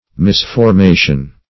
\Mis`for*ma"tion\